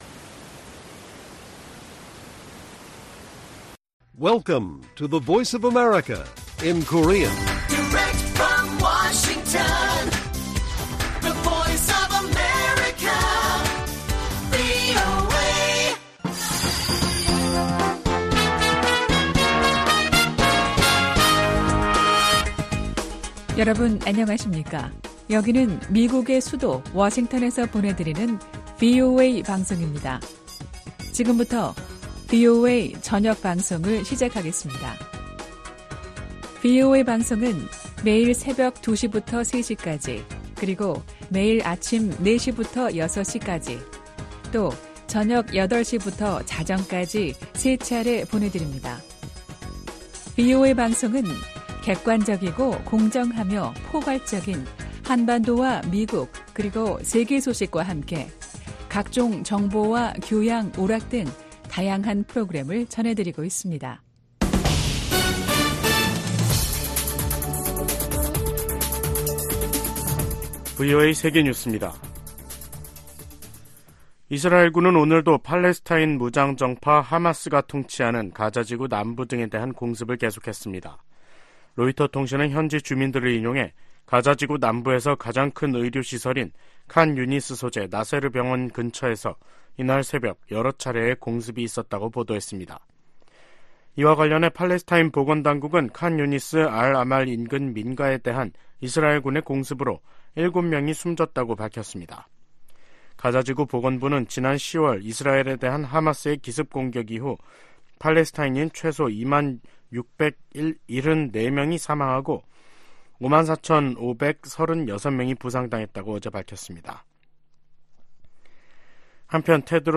VOA 한국어 간판 뉴스 프로그램 '뉴스 투데이', 2023년 12월 26일 1부 방송입니다. 조 바이든 미 대통령이 서명한 2024회계연도 국방수권법안에는 주한미군 규모를 현 수준으로 유지하는 내용과 한반도 관련 새 조항들이 담겼습니다. 북한이 영변의 실험용 경수로를 완공해 시운전에 들어간 정황이 공개되면서 한국 정부는 동향을 예의주시하고 있습니다. 유엔난민기구가 중국 정부에 탈북민의 열악한 인권 실태를 인정하고 개선할 것을 권고했습니다.